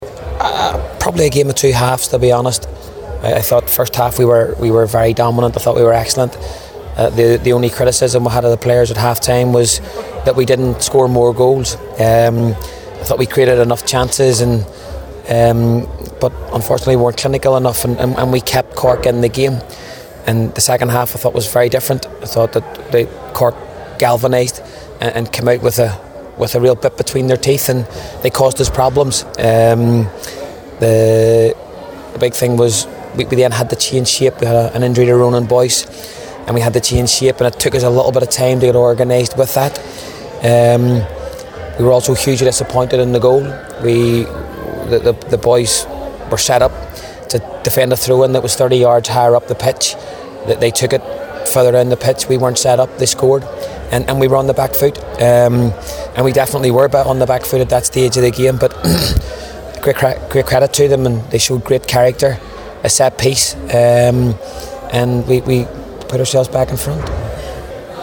Lynch summed up his thoughts afterwards when he spoke to the assembled media.